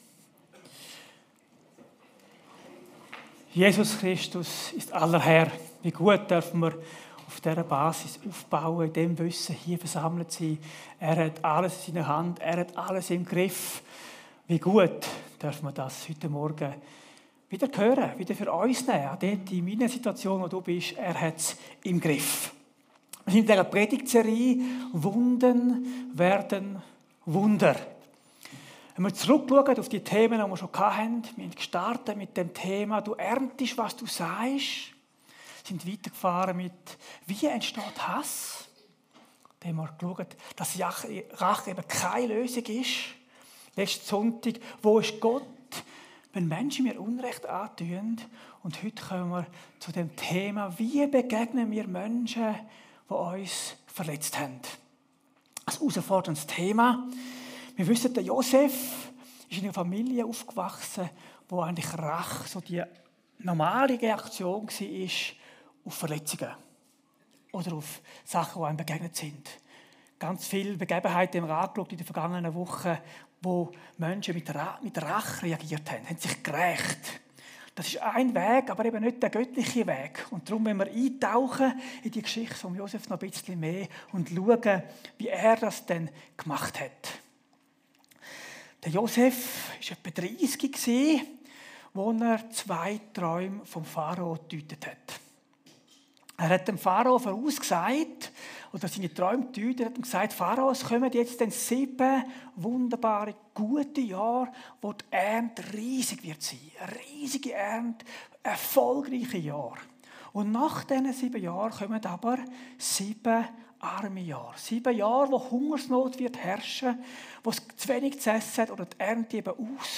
Predigt Wo ist Gott, wenn Menschen mir Unrecht tun?